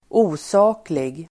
Ladda ner uttalet
Uttal: [²'o:sa:klig]